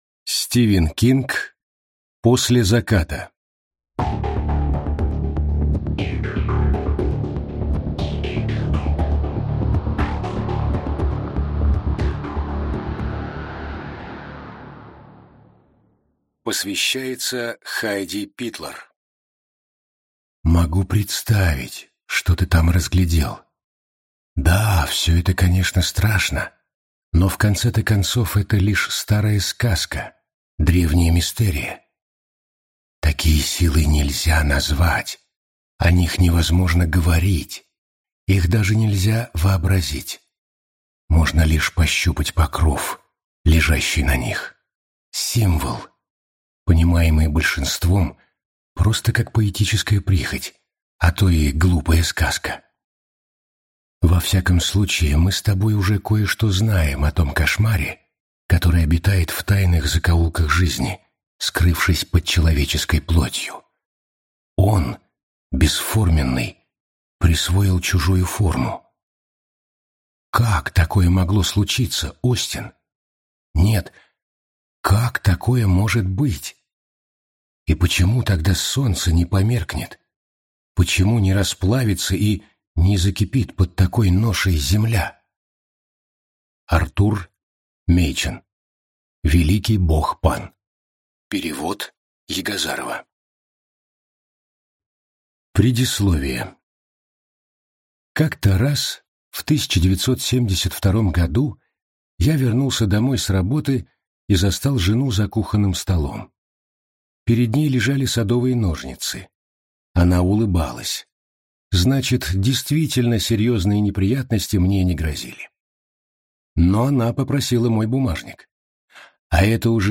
Аудиокнига После заката (сборник) - купить, скачать и слушать онлайн | КнигоПоиск
Аудиокнига «После заката (сборник)» в интернет-магазине КнигоПоиск ✅ Фэнтези в аудиоформате ✅ Скачать После заката (сборник) в mp3 или слушать онлайн